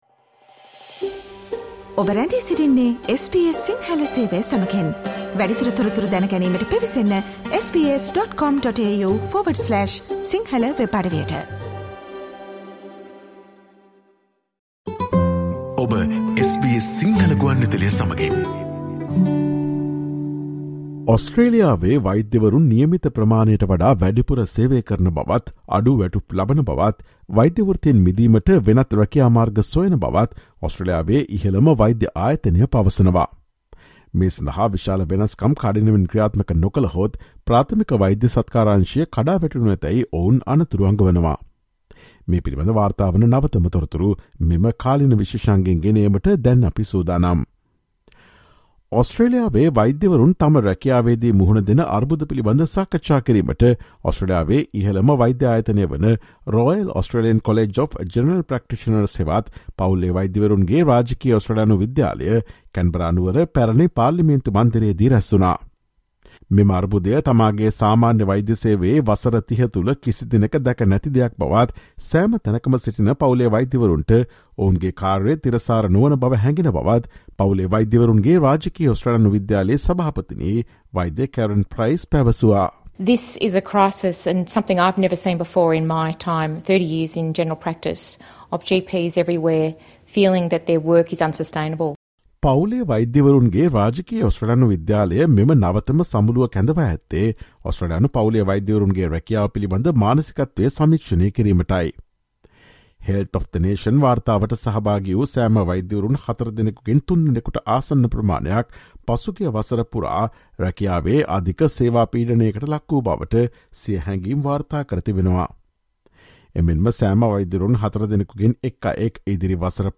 A top medical group says Australia's doctors are increasingly overworked, underpaid, and looking for a path out. Listen to the SBS Sinhala Radio's current affairs feature broadcast on Thursday 06 October.